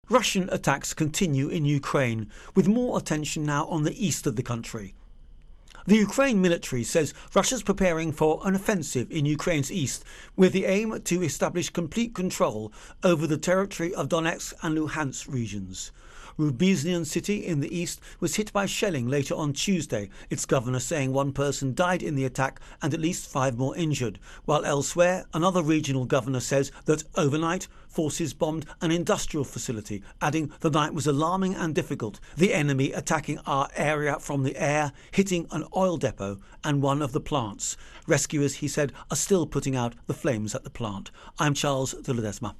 Russia Ukraine War Further Attacks Intro and Voicer